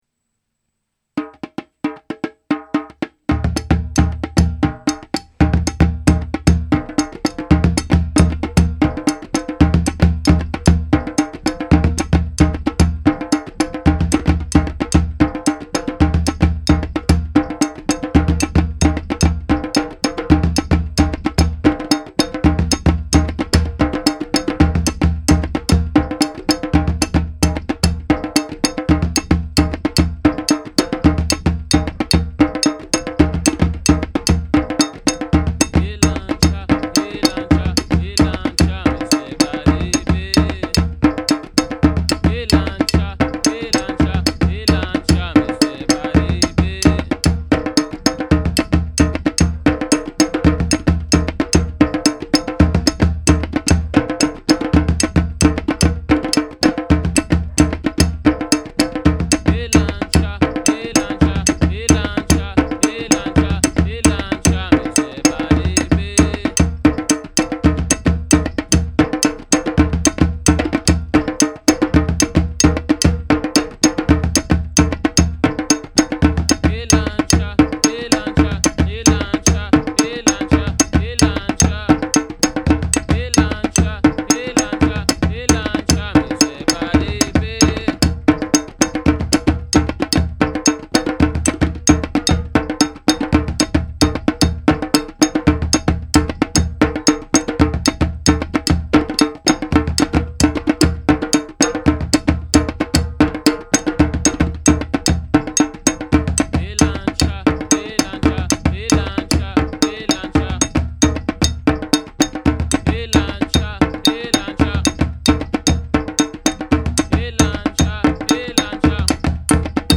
West African rhythm